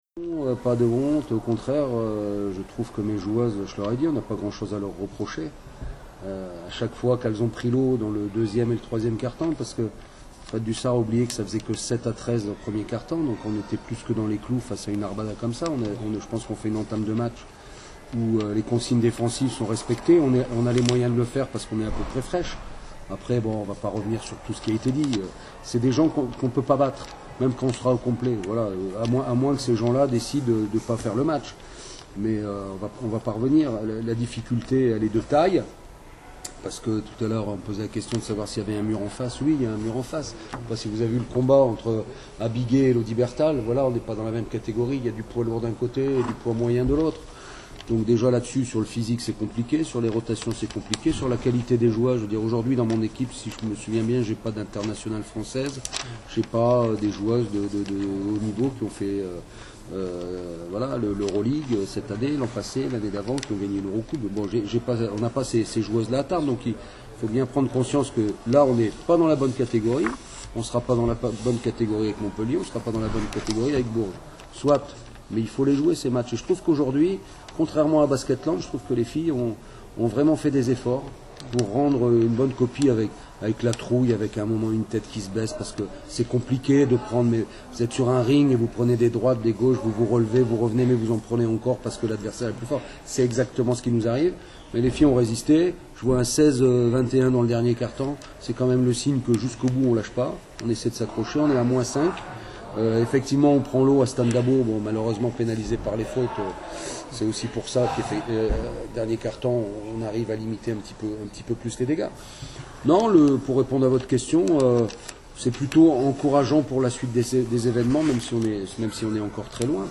Conférence de presse